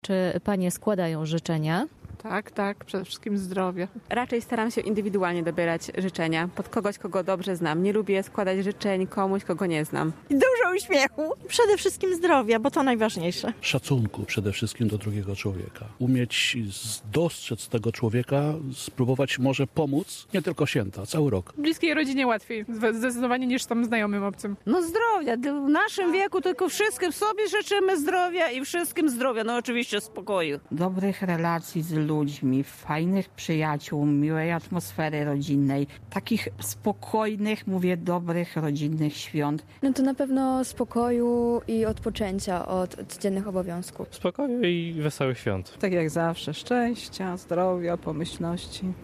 [SONDA] Czego życzą sobie i innym mieszkańcy Rzeszowa z okazji świąt?